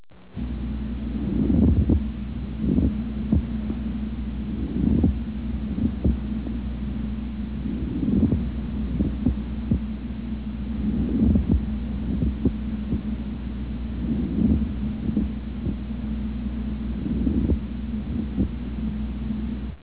Pleural-Friction.mp3